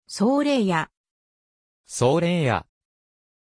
Pronunciation of Sorayah
pronunciation-sorayah-ja.mp3